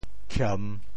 How to say the words 欠 in Teochew？
TeoChew Phonetic TeoThew kiem3